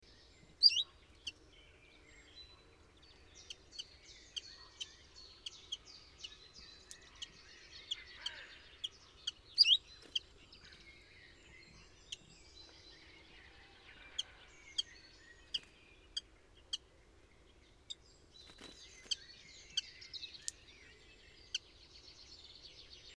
PFR02651, 090511, Common Chiffchaff Phylloscopus collybita, calls, plastic song